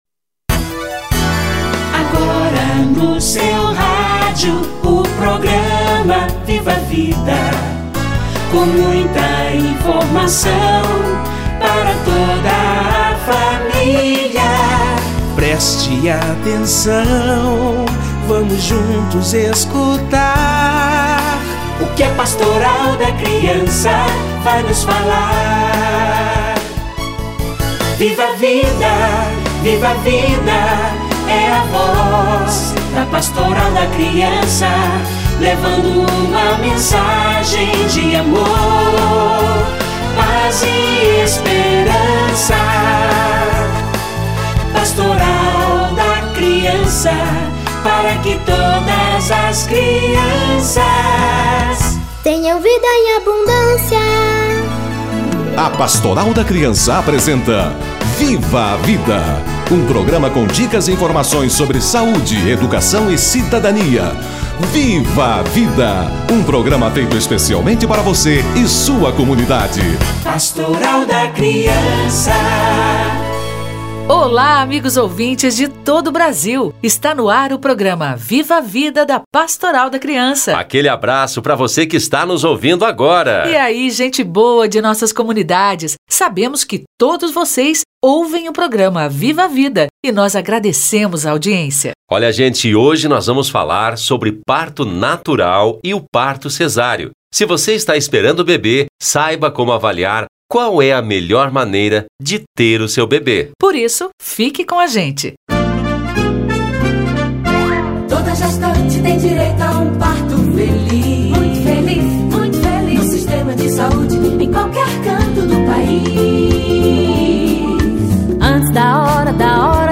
Parto normal e cesárea - Entrevista